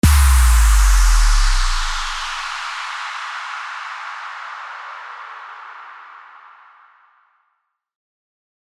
FX-654-IMPACT
FX-654-IMPACT.mp3